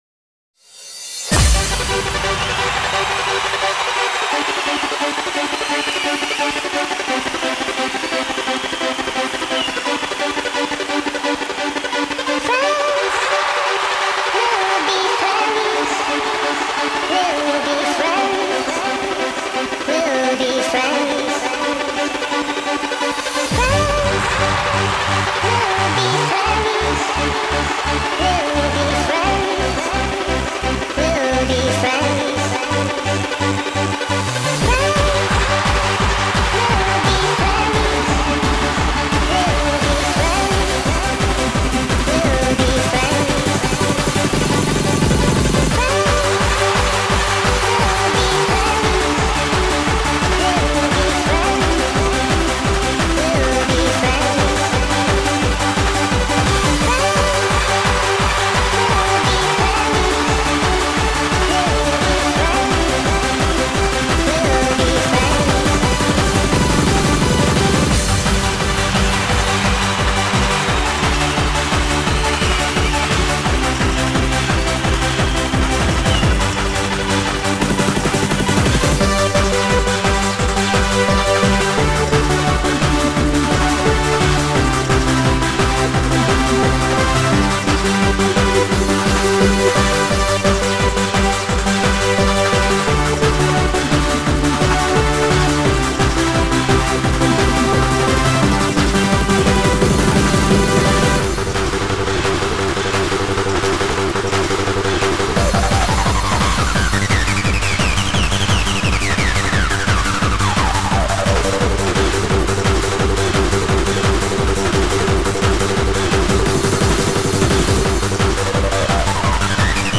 extended remix
• Jakość: 44kHz, Stereo